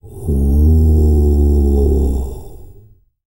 TUVANGROAN11.wav